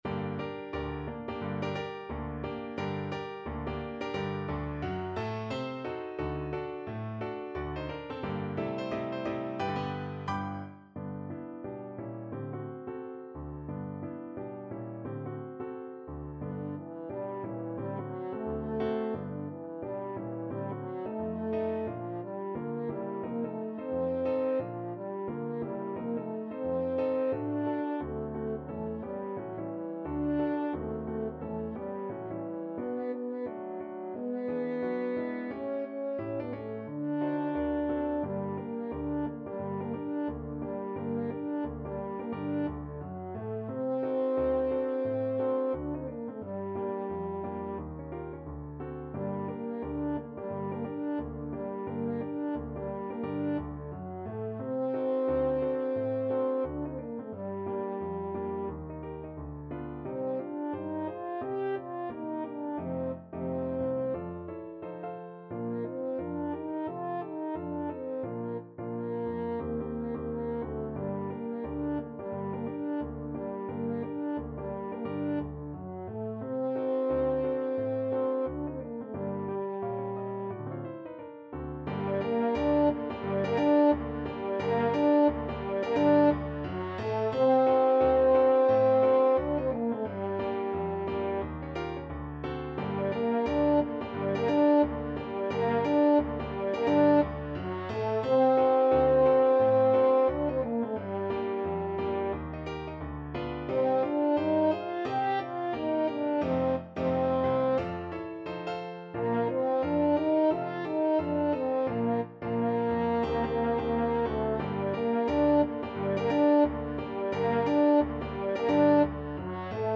2/2 (View more 2/2 Music)
~ = 176 Moderato
Jazz (View more Jazz French Horn Music)